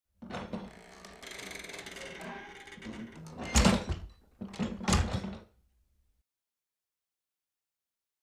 Creak, Door
Heavy Wood Door, Metal Latch Open With Long Creaks And Rattle With Close